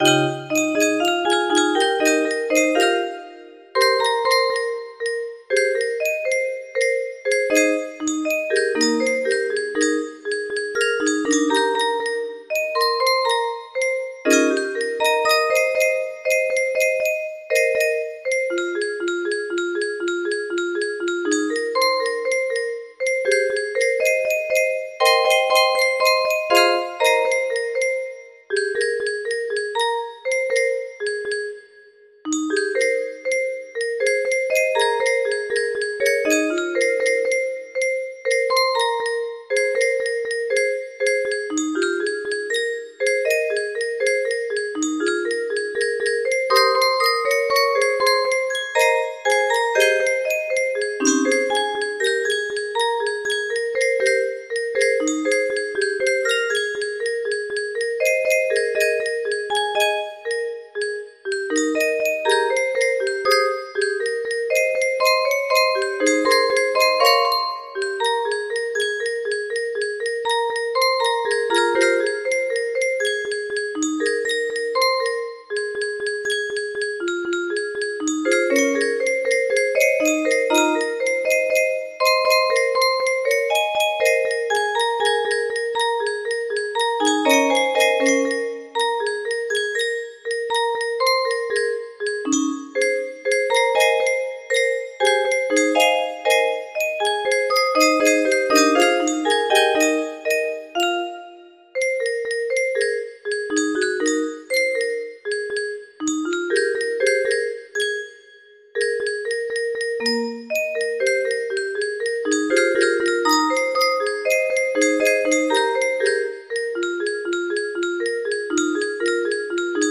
? music box melody
Full range 60